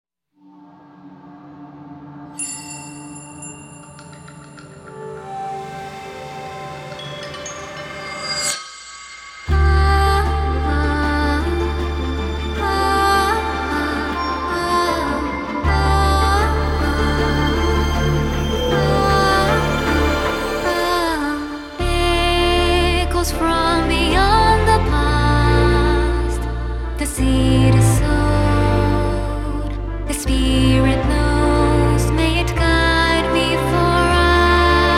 Струнные и рояль
Classical Crossover
Жанр: Классика